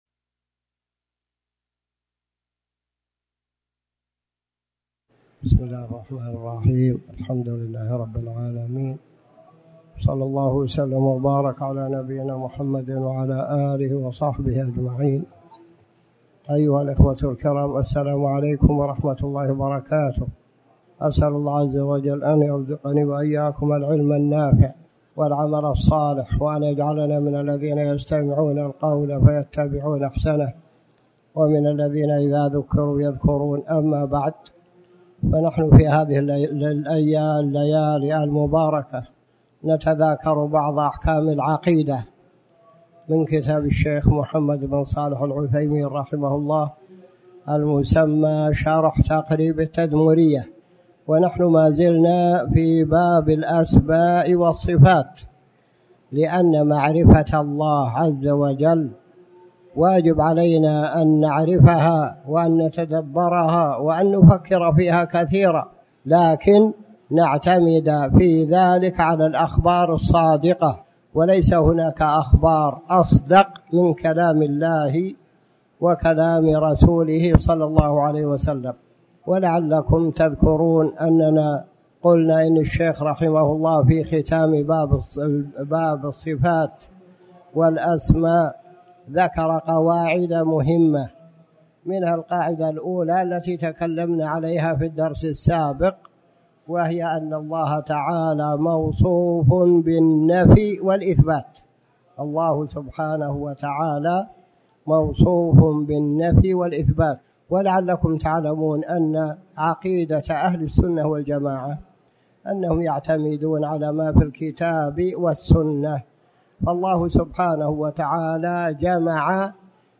تاريخ النشر ٢٢ ذو القعدة ١٤٣٩ هـ المكان: المسجد الحرام الشيخ